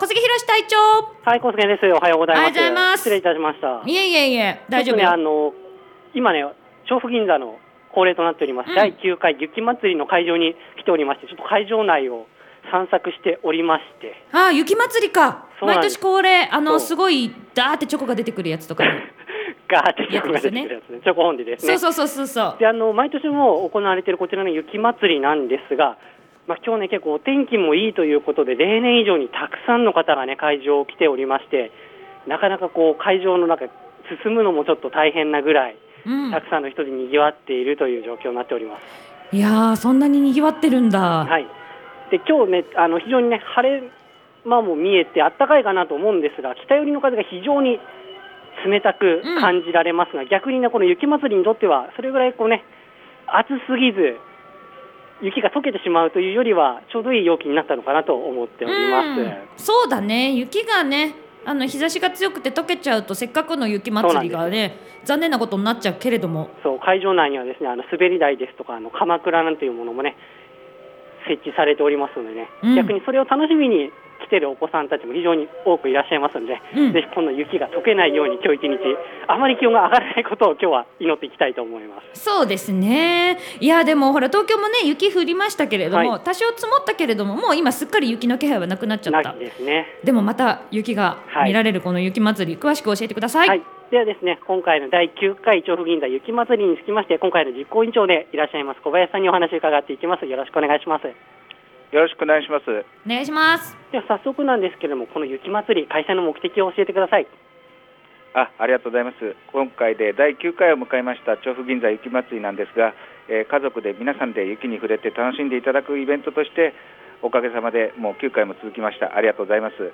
今日は「第9回　調布銀座雪まつり」が開催されているから。